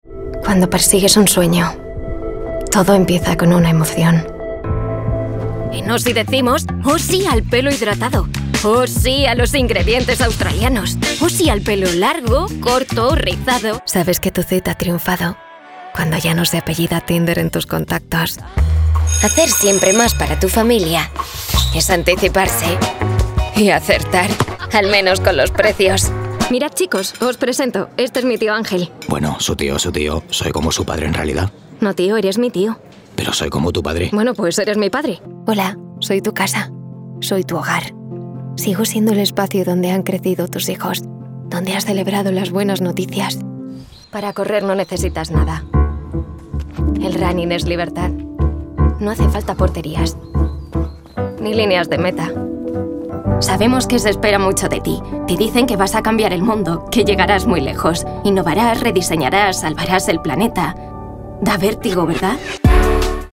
Young, Urban, Cool, Reliable, Natural
Commercial